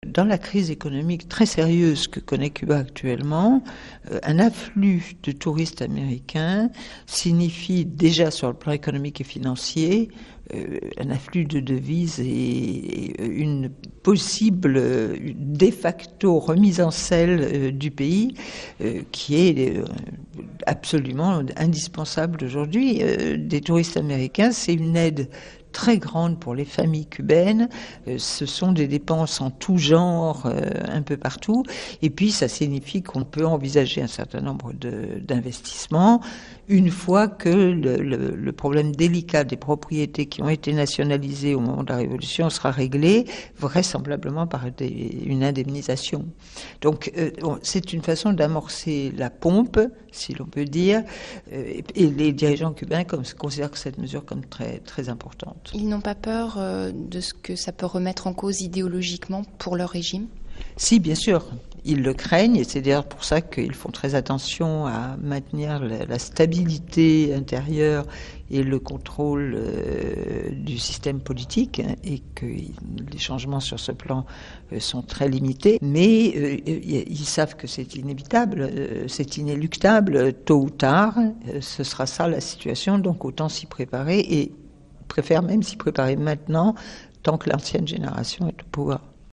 Entretien radiophonique diffusé sur RFI, le 23 octobre 2009.